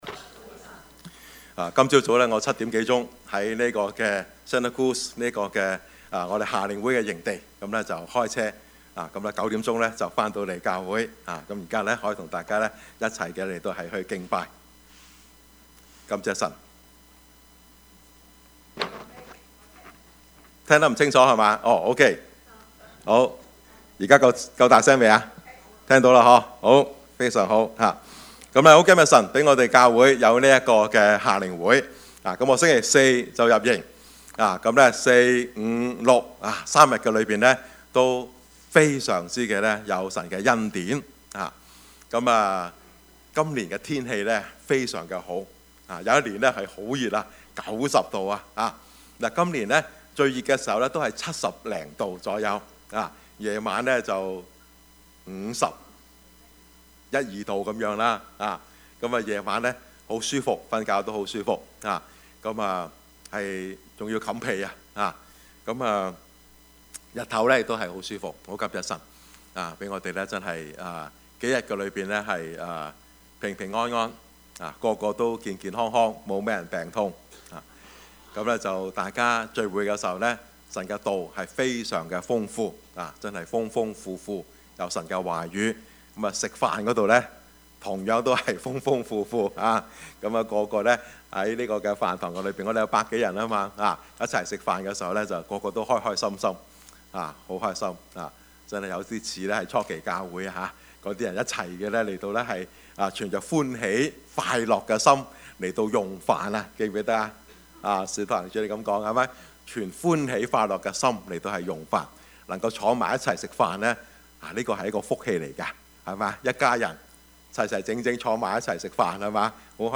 Service Type: 主日崇拜
Topics: 主日證道 « 2019 夏令營 同心合意爭戰 »